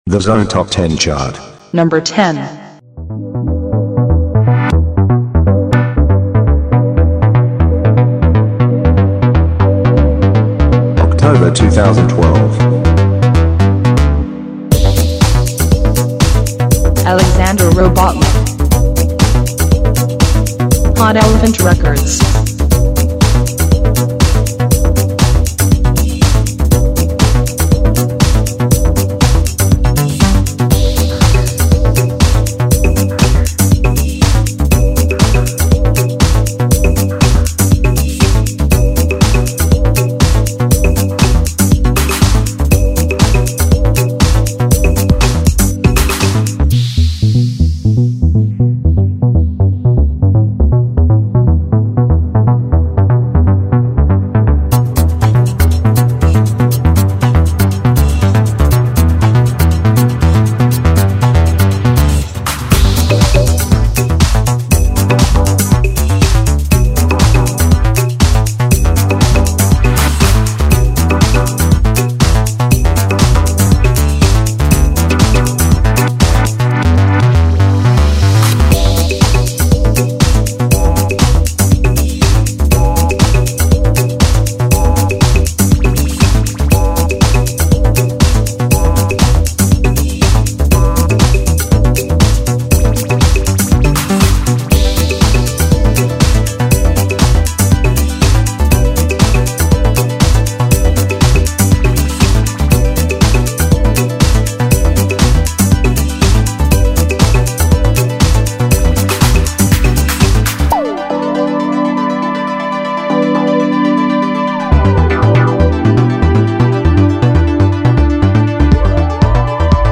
For all the Electronic/Electro music lovers!
60-minute electronic atmospheres